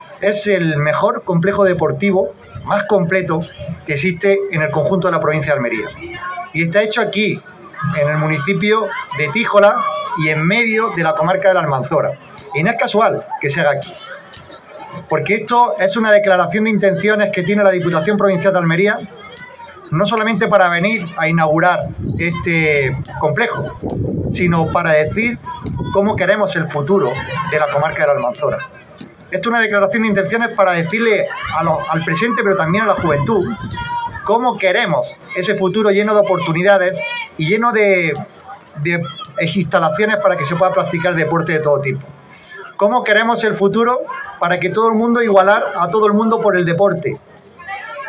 Presidente-de-la-Diputacion.mp3